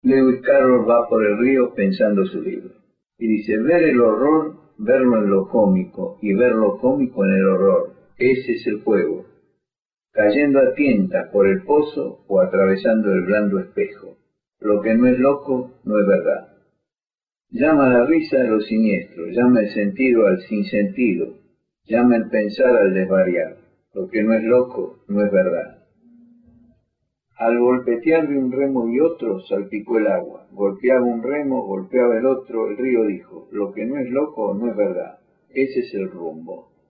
Leónidas Lamborghini lee Lewis Carrol en Radio Nacional.mp3